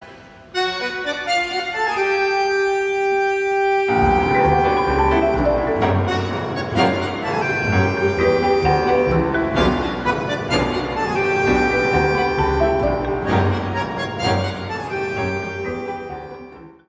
Play, download and share Tango_ original sound button!!!!
tango-piazzolla-boda-r.mp3